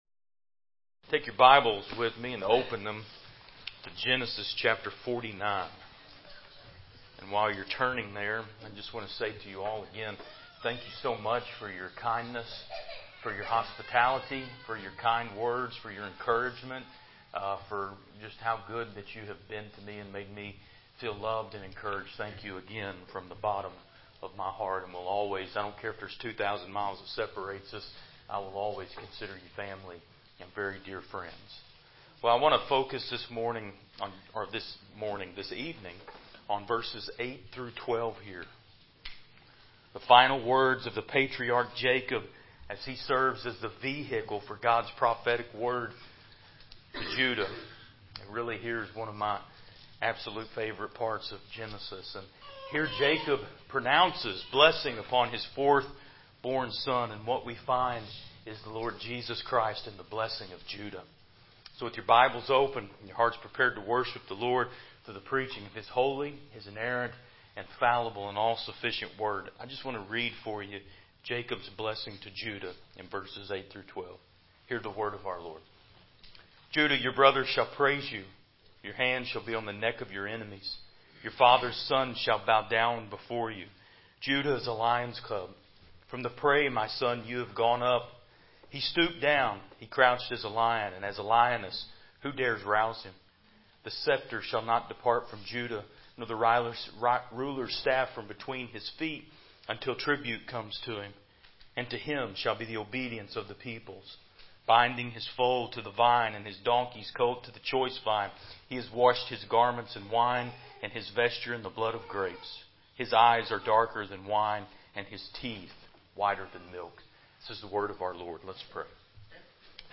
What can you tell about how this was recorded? Genesis 49:8-12 Service Type: Evening Worship « Remember Who You Were Chapter 15.1-2